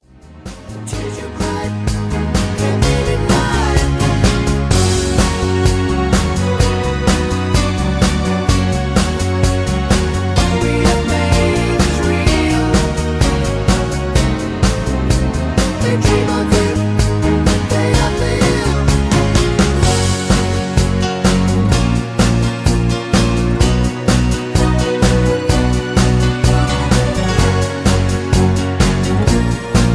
Karaoke MP3 Backing Tracks
Just Plain & Simply "GREAT MUSIC" (No Lyrics).
mp3 backing tracks